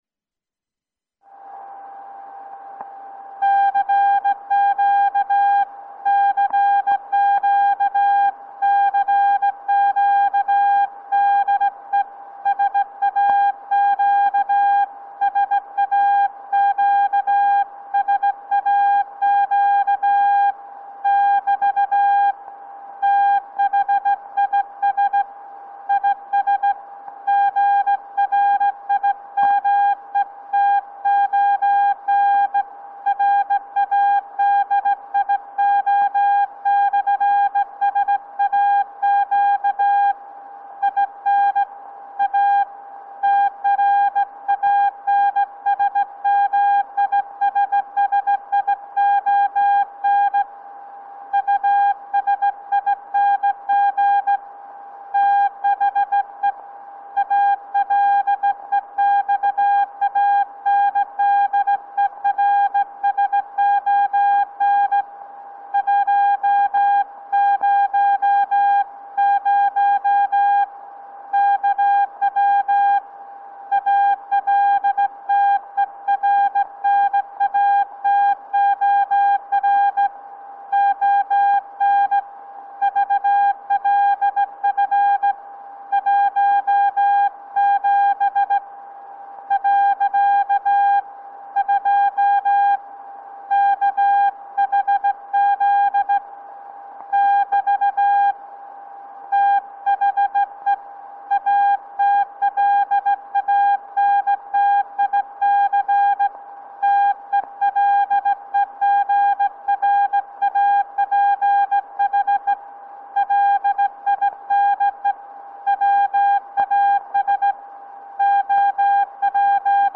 En mer än 40 år gammal mottagare för mottagning av Grimeton/SAQ på 17,2 kHz. En okonventionell VLF-mottagare, superheterodyn utan induktanser.
I filen hör man hur tonen stiger ett kort tag när sändaren slås av och frekvensen går ner, detta beror på att lokaloscillatorn ligger högre än signalfrekvensen.